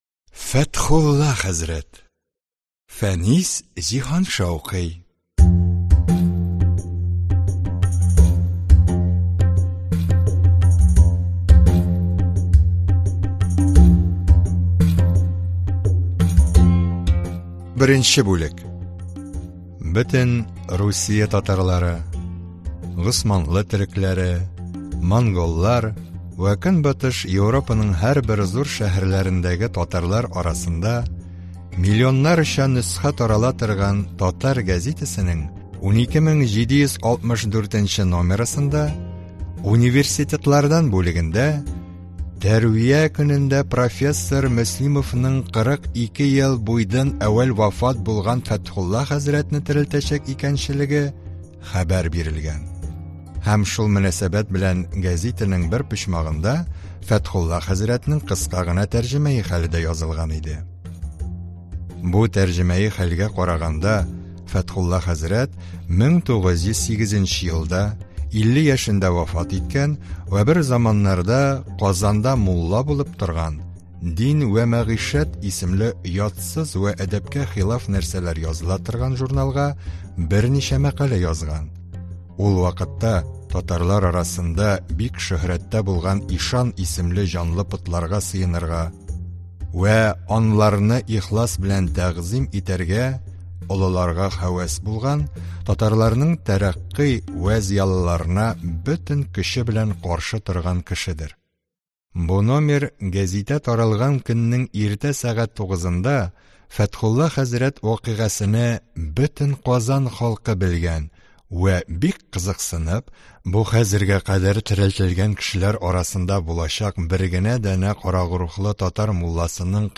Аудиокнига Фәтхулла хәзрәт | Библиотека аудиокниг